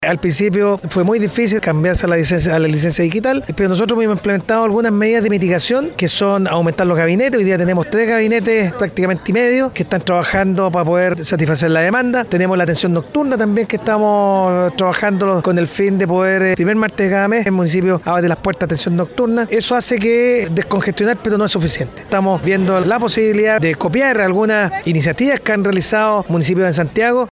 Por su parte, el alcalde de Villa Alemana, Nelson Estay, indicó que aumentaron el gabinete para satisfacer la demanda, y señaló que pretenden replicar medidas adoptadas en Santiago.